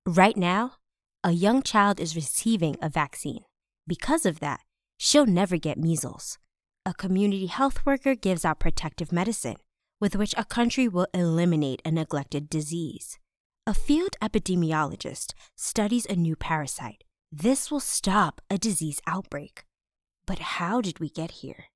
Documentary
My voice is cheerful, youthful. bright, distinctive and versatile.